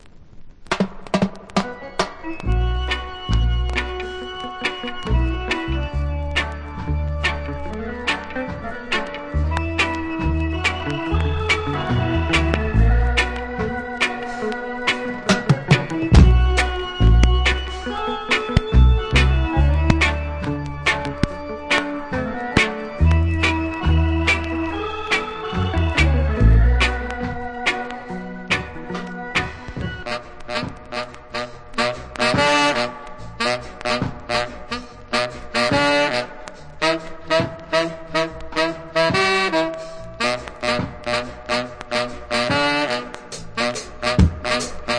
Killler Inst.